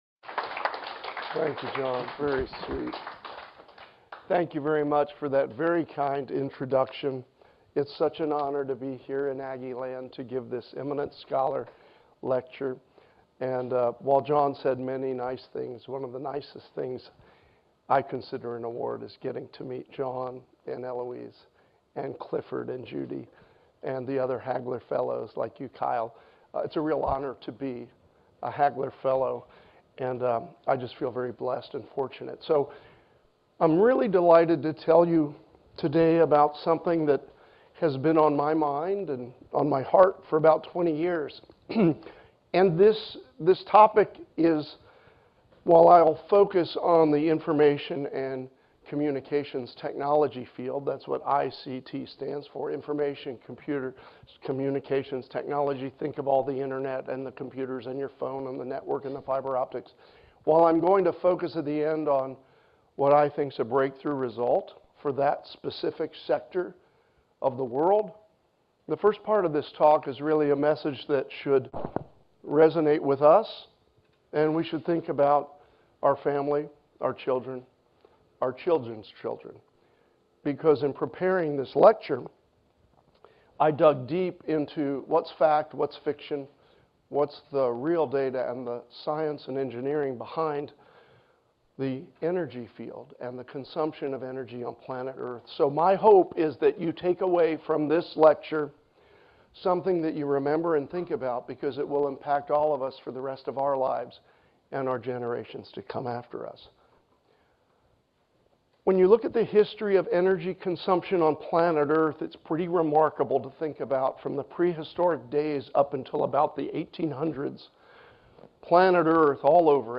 Eminent Scholar Lecture